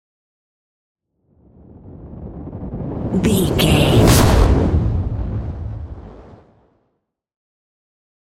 Dramatic whoosh to hit trailer
Sound Effects
Fast paced
In-crescendo
Atonal
dark
intense
tension
woosh to hit